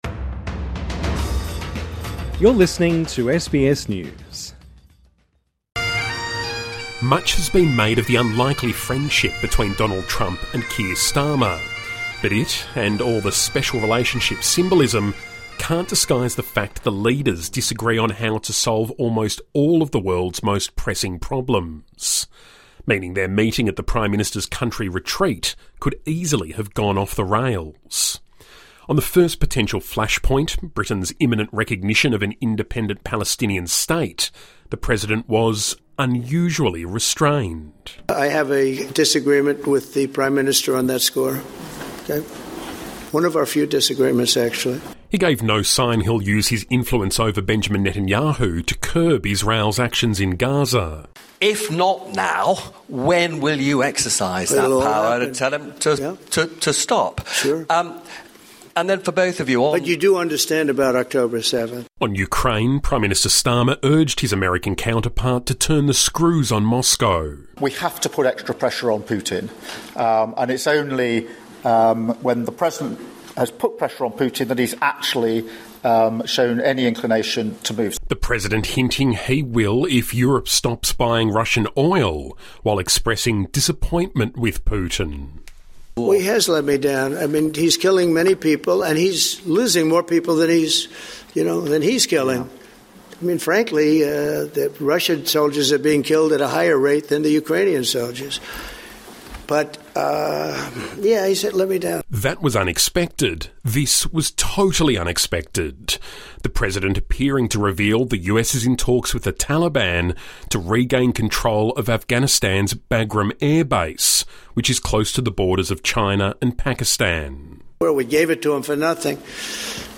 Donald Trump says Vladimir Putin has “let him down”, and revealed the US is working to regain control of a key air base in Afghanistan, during a wide-ranging press conference that marked the end of his state visit to the United Kingdom. British Prime Minister Keir Starmer farewelled his guest - relieved the trip went without any major controversy.